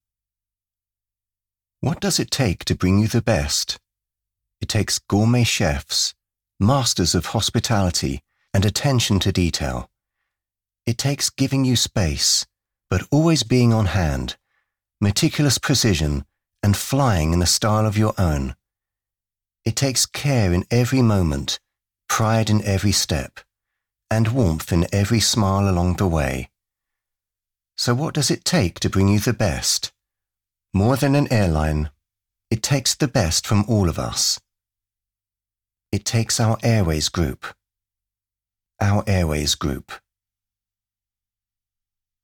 Male
Adult (30-50), Older Sound (50+)
His naturally deep, distinctively textured voice combines clarity, warmth and authority, making it well suited to commercials, explainers, corporate narration, training content and character roles.
Television Spots
British English Male Airline Ad